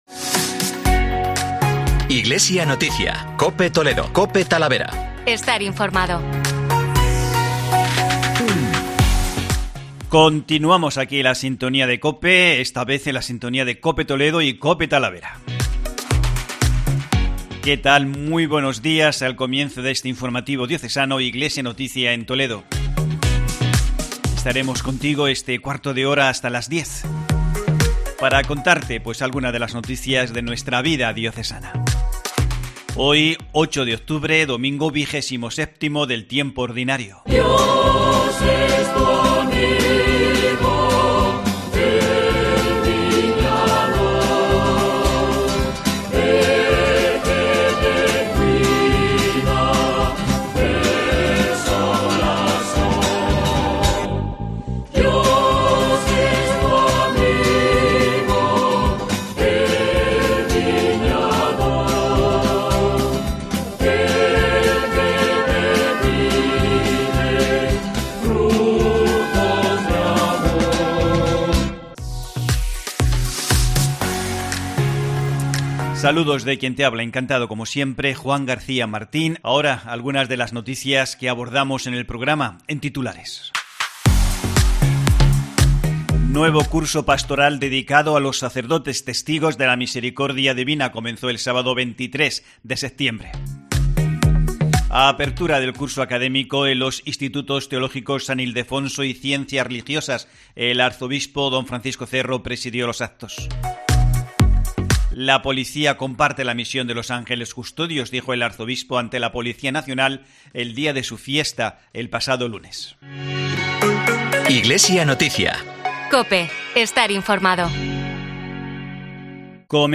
Informativo Diocesano Iglesia en Toledo